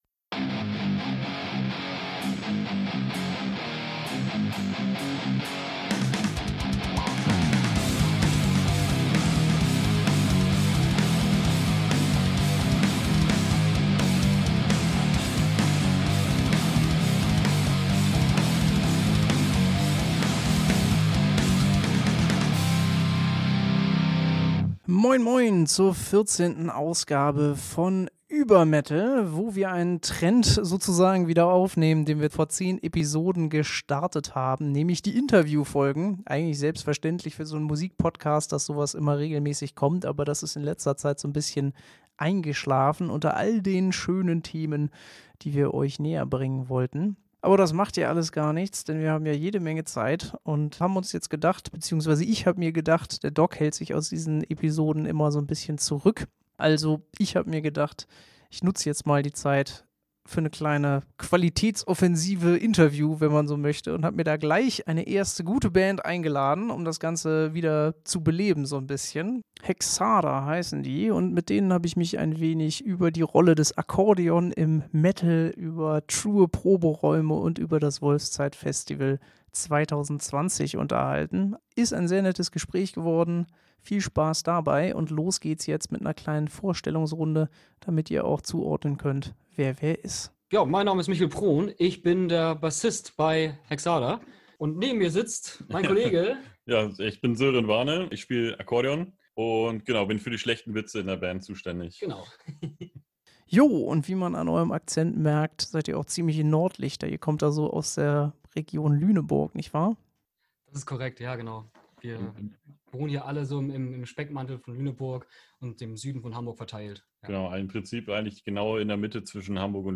Beschreibung vor 5 Jahren Nach zehn Themen-Episoden gibt's heute mal wieder ein Interview zu hören. Und zwar mit niemand geringerem als mit den geometriebegeisterten Underground-Akkordeon-Extremmetallern von Hexadar.